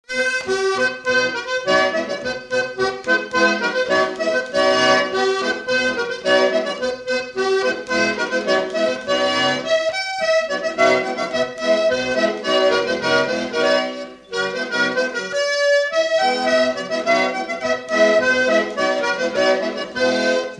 mazurka jouée à la fin du quadrille
Chants brefs - A danser
danse : mazurka
Pièce musicale inédite